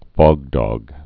(fôgdôg, -dŏg, fŏg-)